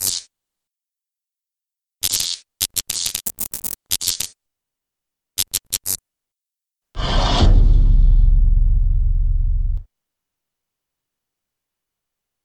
antimatter_flicker.mp3